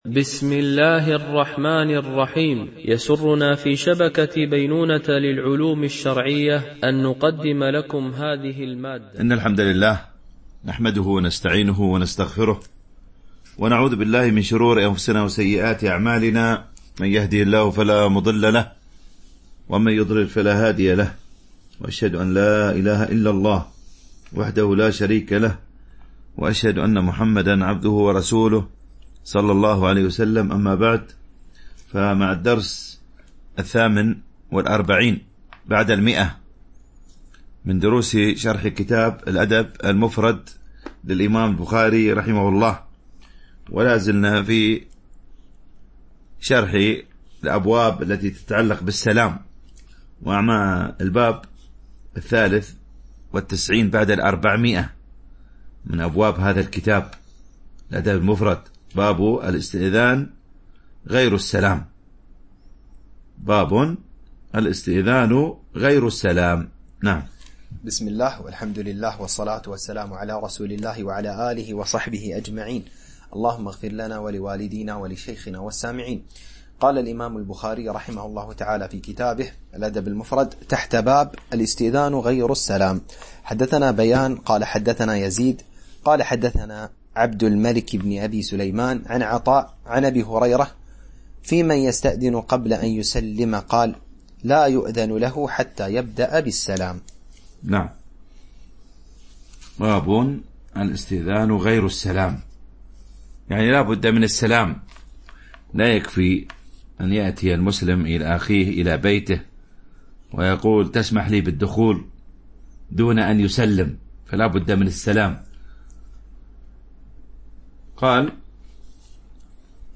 شرح الأدب المفرد للبخاري ـ الدرس 148 ( الحديث 1066 - 1073 )